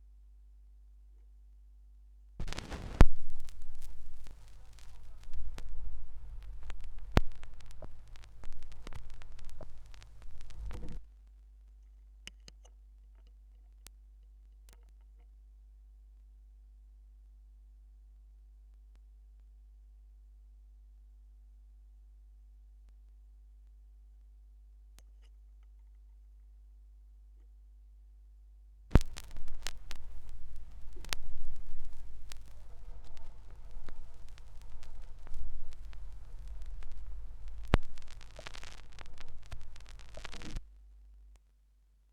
2017 Schallplattengeräusche (3)
Leere Phrasen (Leerstellen auf Schallplatten, die digitalisiert werden.)
Digitalisierung: Thorens TD 165 Special, Orthophon Pro System, Tascam HD-P2 (24 Bit, 48 KHz), Audacity